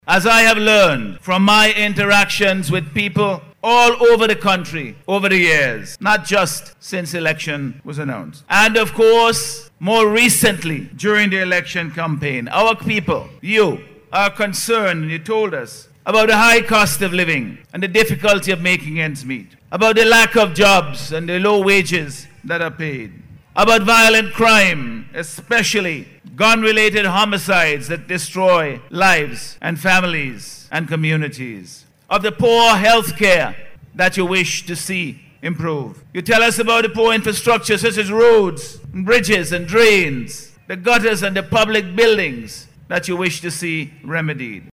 Prime Minister, Dr. Godwin Friday made this statement during Tuesday’s Swearing in ceremony for this country’s new Cabinet.